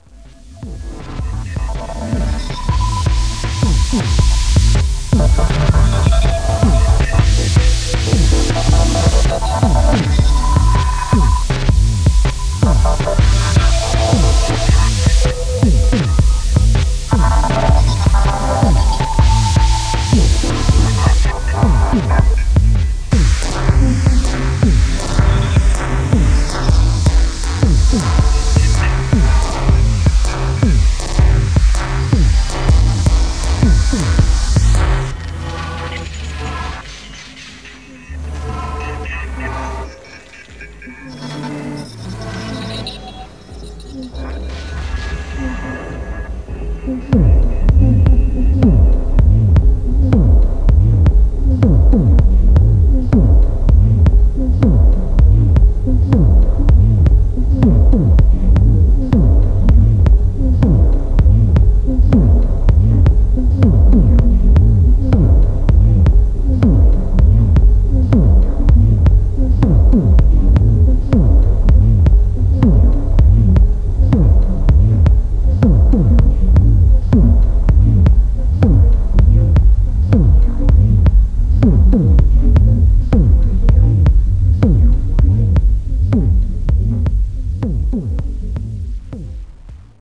ハーフタイム、ポリリズムが錯綜、自動生成されてゆくような、テクノの何か得体の知れない領域が拡大しています。